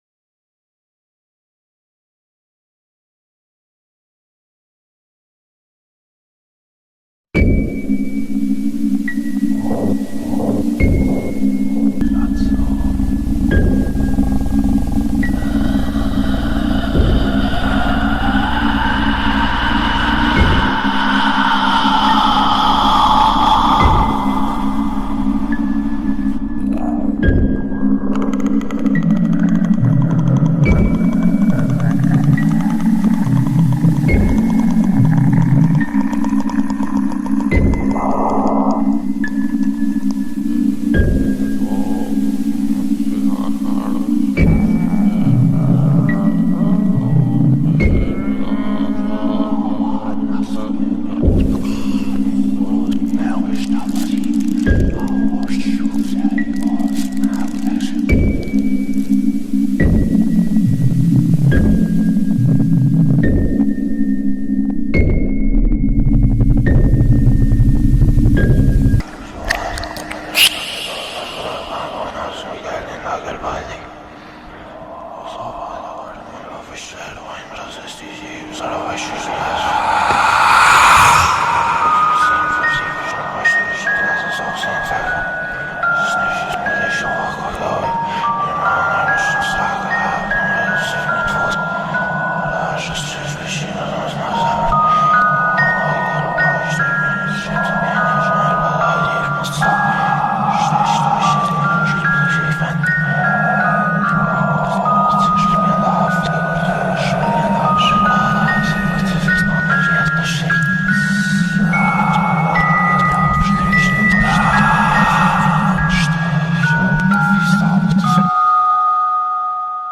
Te-reto-a-escuchar-esto-con-los-ojos-cerrados-Audio-8D---Terror-Auditivo.mp3
LAved7JghDa_Te-reto-a-escuchar-esto-con-los-ojos-cerrados-Audio-8D---Terror-Auditivo.mp3